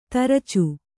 ♪ taracu